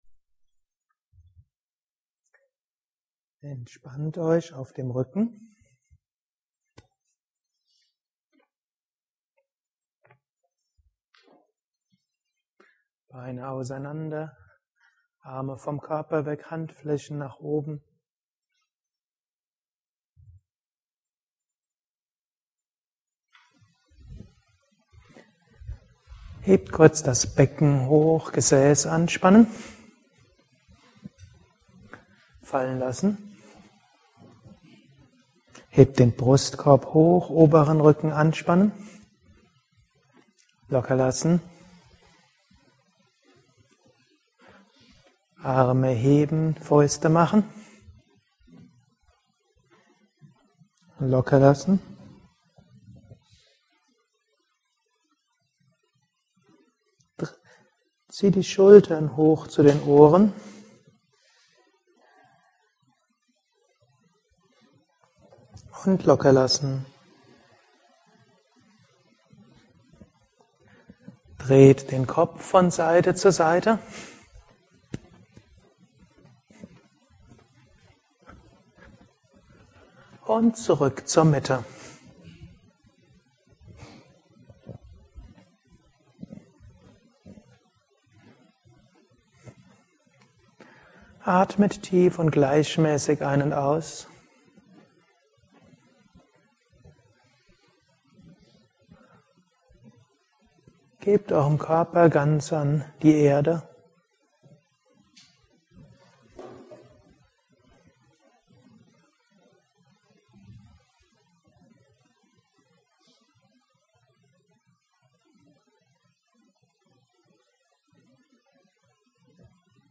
Du übst Asanas - dies ist keine reine Philosophen-Yogastunde. Aber in den Asanas fragst du dich: Wer bin ich? Bodyscan Tiefenentspannung.